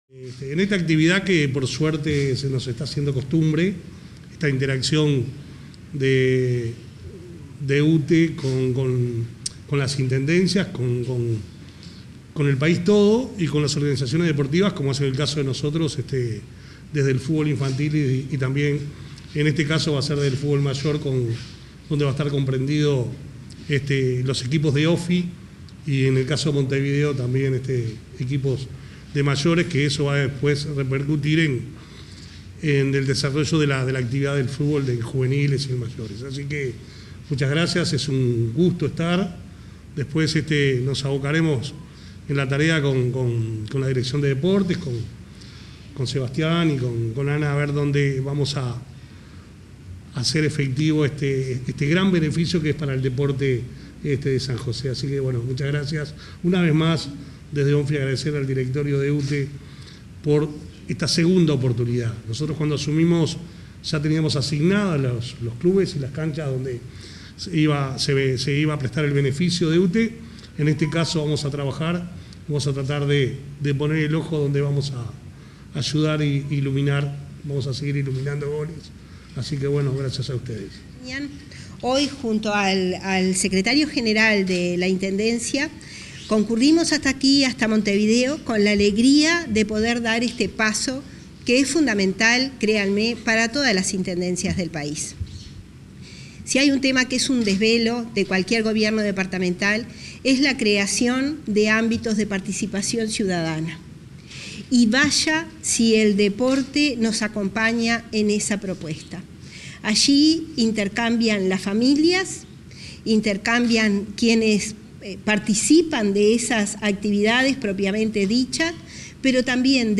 Ceremonia de firma de convenio entre Intendencia de San José y UTE